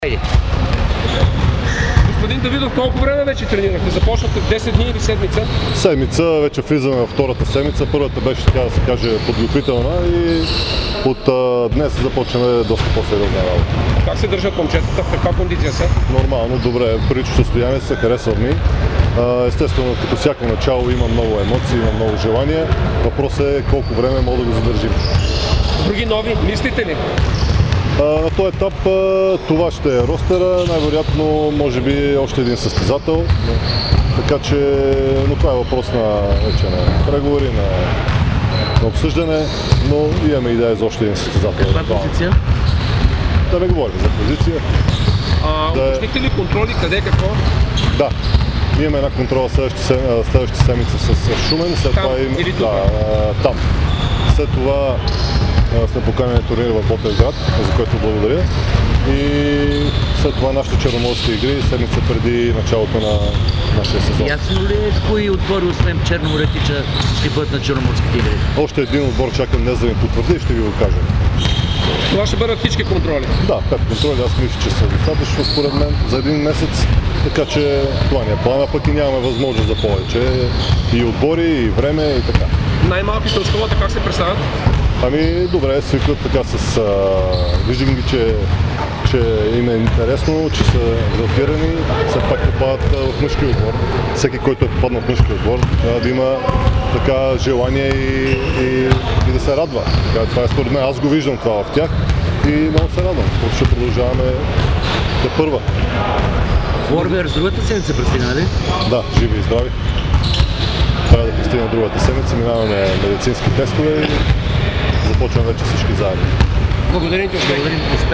каза пред медиите днес при откритата тренировка